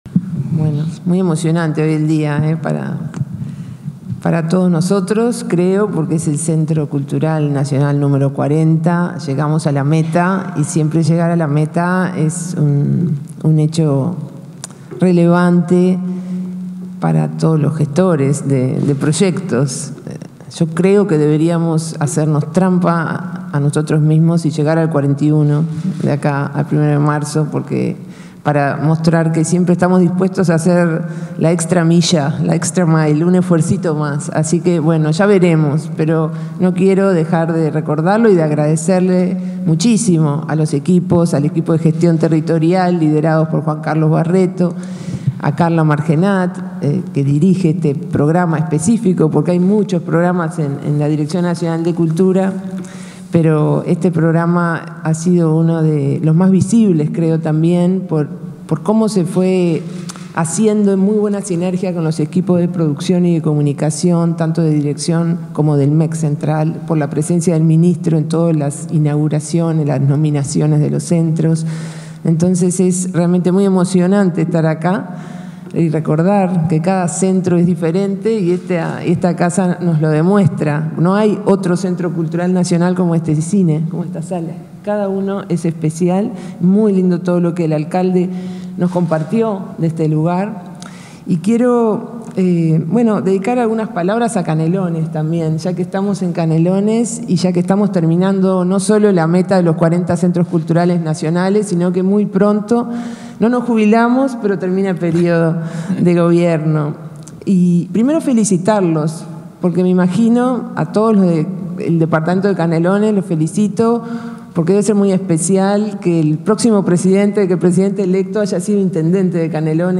Este 12 de diciembre, se realizó el acto de declaración como Centro Cultural Nacional del Complejo Cultural Lumiere de Canelones.
Participaron el ministro de Educación y Cultura, Pablo da Silveira, y la directora nacional de Cultura, Mariana Wainstein.